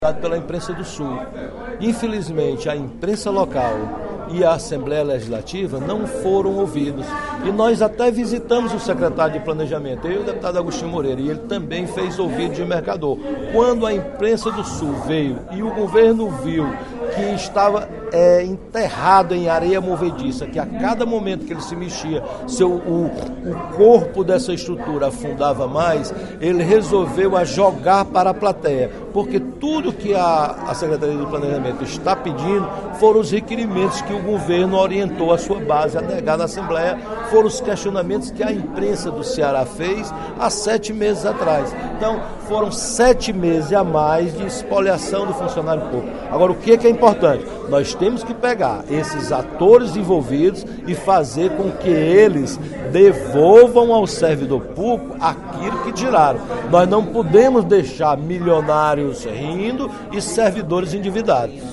O deputado Roberto Mesquita (PV) defendeu nesta terça-feira (10/04), em pronunciamento na Assembleia Legislativa, a instalação de uma Comissão Parlamentar de Inquérito (CPI) para apurar as supostas irregularidades envolvendo agentes financeiros responsáveis pela administração dos empréstimos consignados a servidores do Estado. O parlamentar quer uma investigação que leve bancos e corretoras a devolverem os lucros auferidos com as operações.